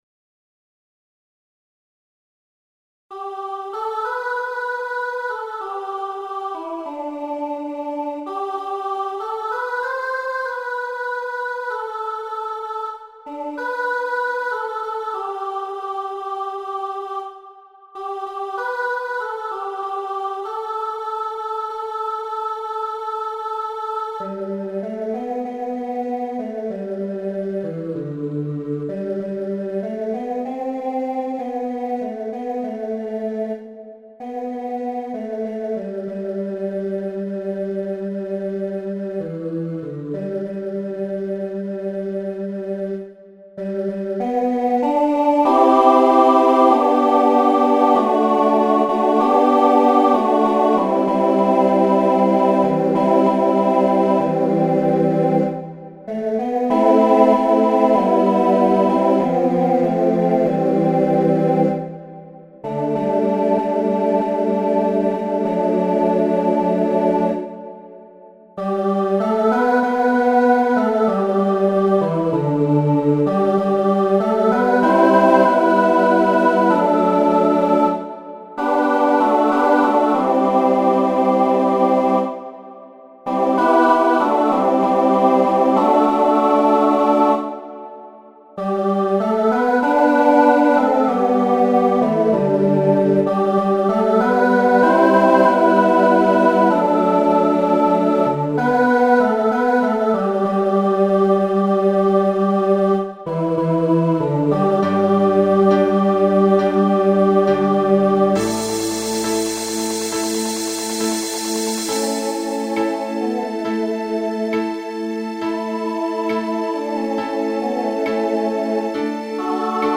Voicing SATB Instrumental combo Genre Country , Pop/Dance
2000s Show Function Ballad